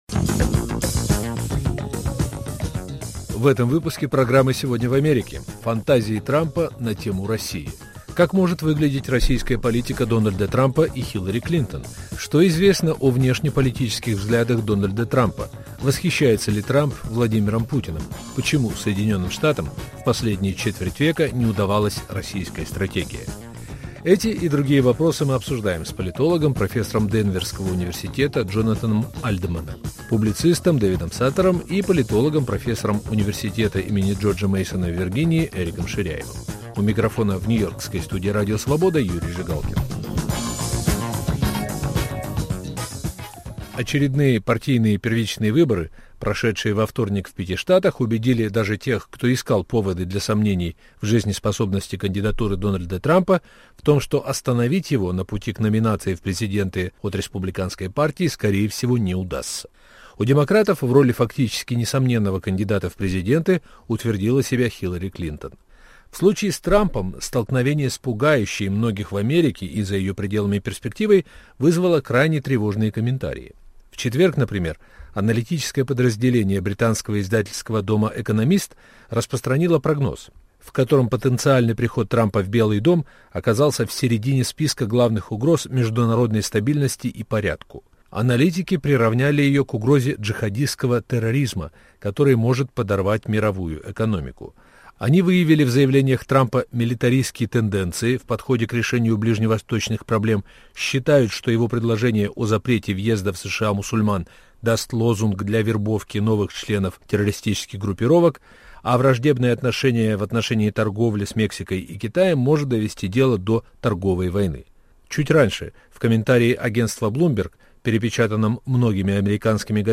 обсуждают американские эксперты